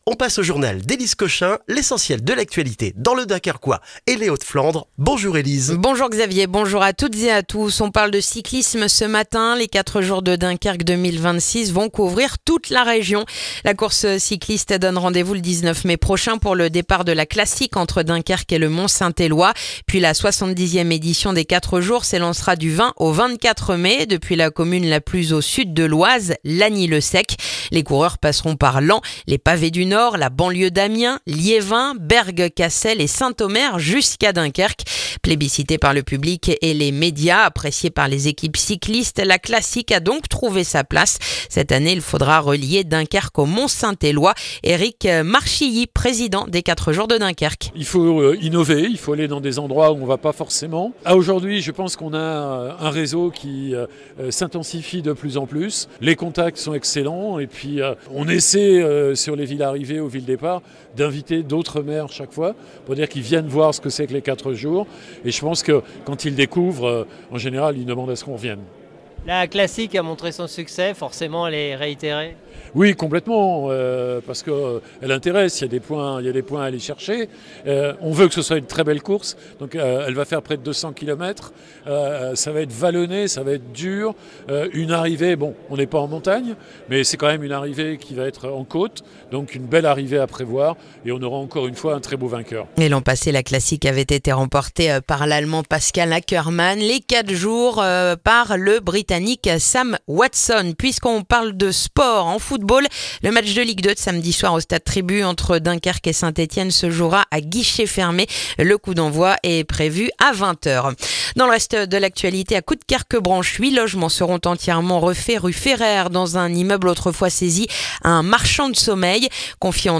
Le journal du mercredi 3 décembre dans le dunkerquois